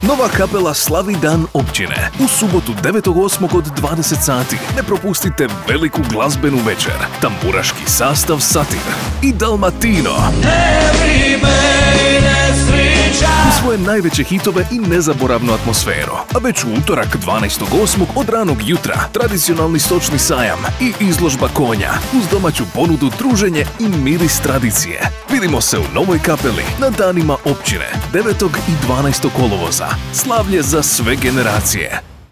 Comercial, Seguro, Amable
Explicador